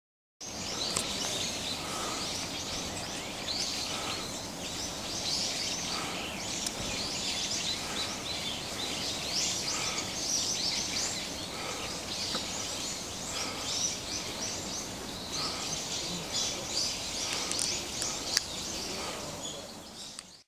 小鳥の鳴き声を録音していた。
山間に響く楽しげな鳴き声に混じって、少し苦しそうな会長の息づかいも入っているのが惜しい。